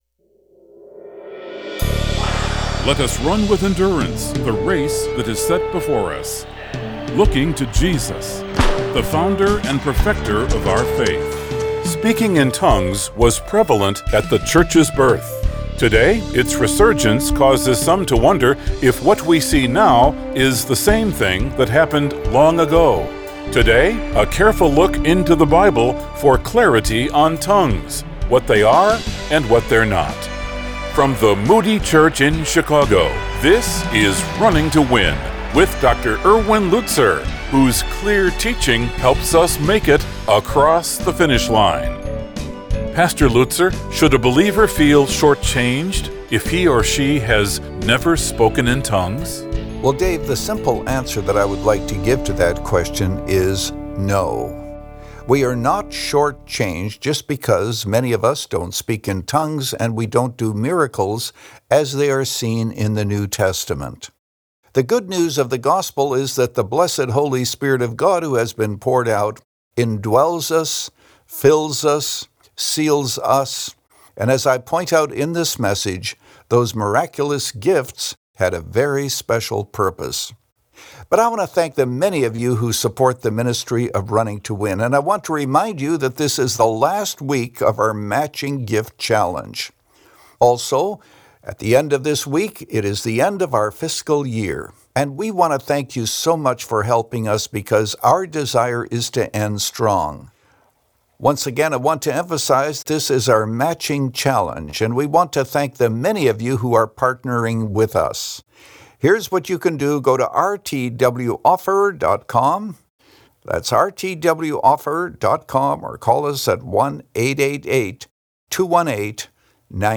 Since 2011, this 25-minute program has provided a Godward focus and features listeners’ questions.